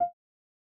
Sound / Effects / UI / Modern2.wav